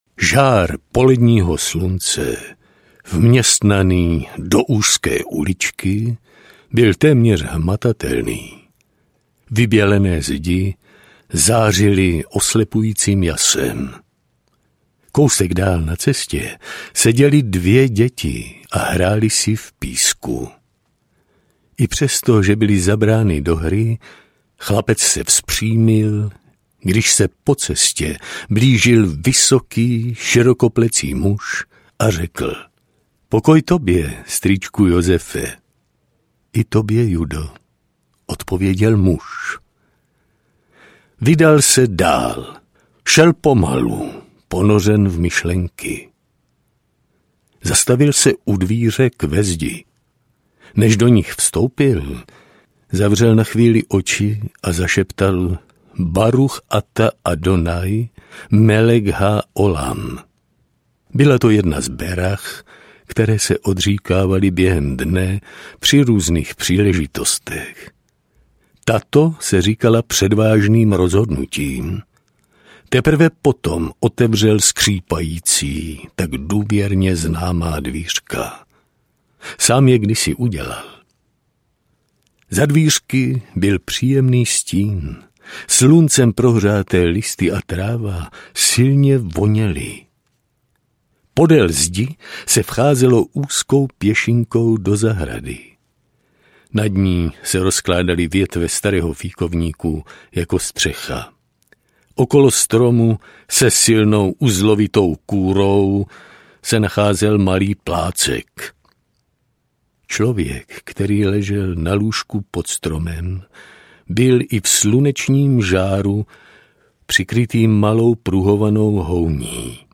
Stín Otce audiokniha
Ukázka z knihy